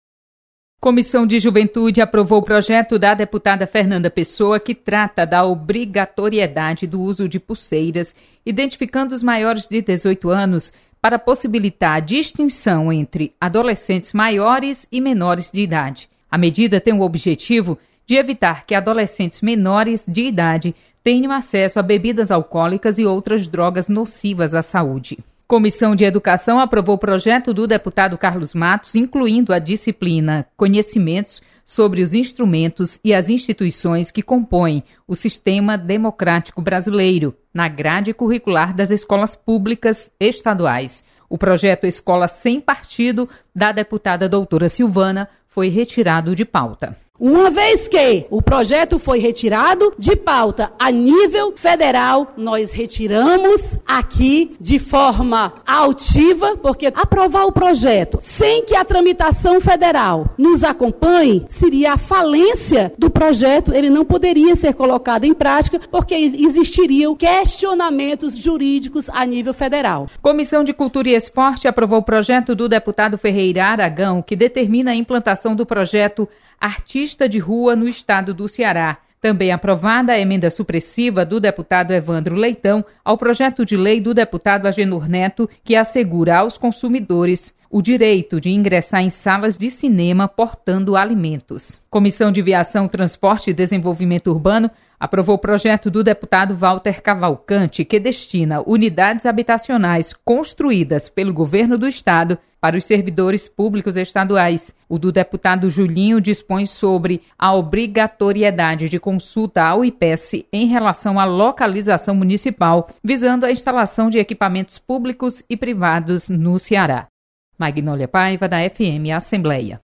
Acompanhe balanço das comissões técnicas permanentes da Assembleia Legislativa. Repórter